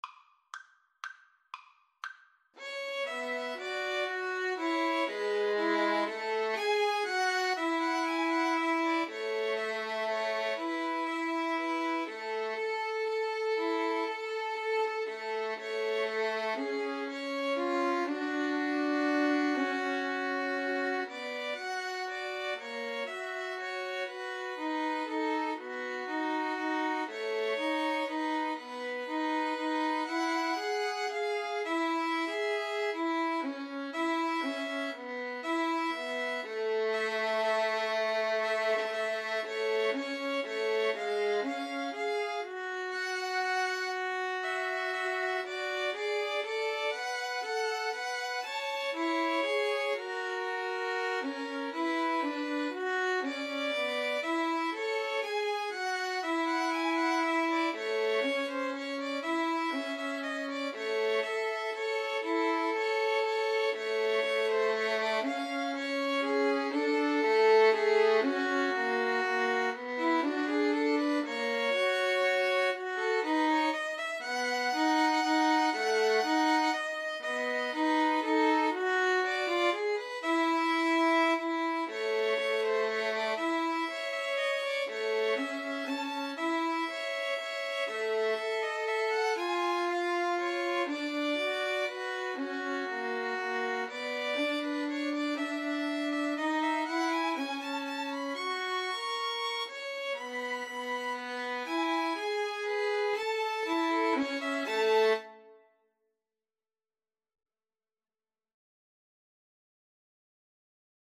Free Sheet music for Violin Trio
A major (Sounding Pitch) (View more A major Music for Violin Trio )
= 120 Tempo di Valse = c. 120
3/4 (View more 3/4 Music)